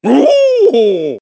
One of Donkey Kong's voice clips in Mario Kart 7